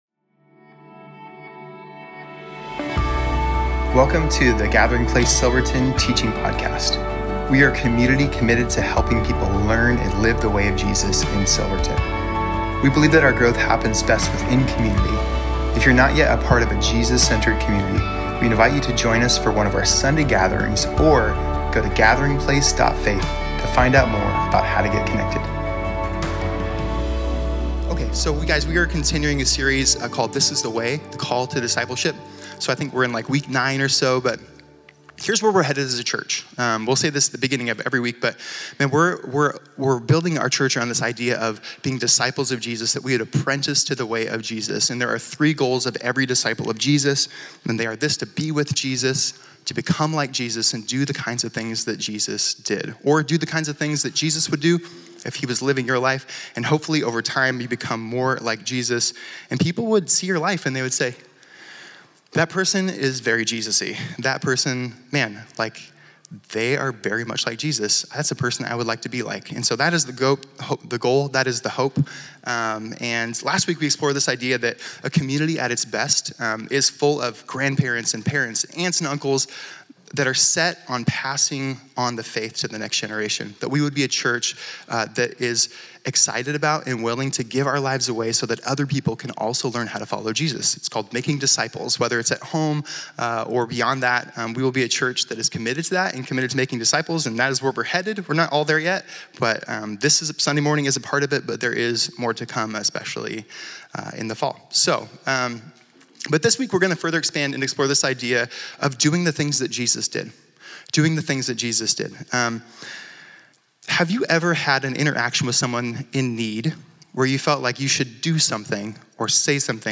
Home About Connect Events Sermons Give This is The Way - A Heart of Compassion June 26, 2025